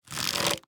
Minecraft Version Minecraft Version snapshot Latest Release | Latest Snapshot snapshot / assets / minecraft / sounds / item / crossbow / quick_charge / quick3_1.ogg Compare With Compare With Latest Release | Latest Snapshot